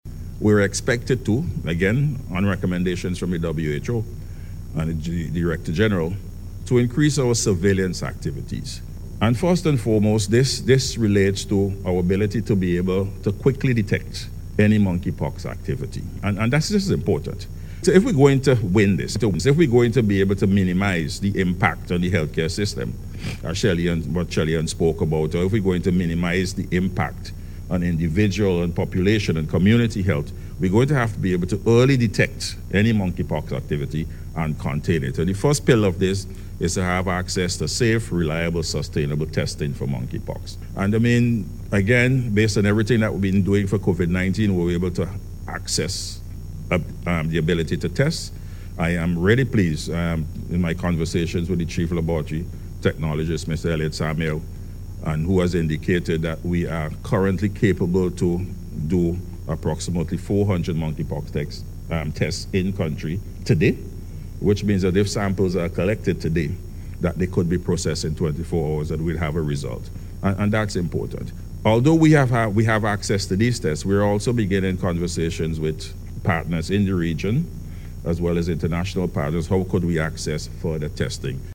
Dr. Duncan was speaking during a Health Symposium hosted by the Ministry of Health last night to update the nation on measures in place to detect and contain a possible spread of the disease.